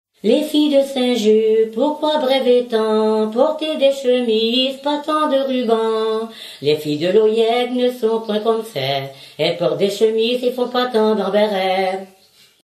Genre brève
Chanteuse du pays de Redon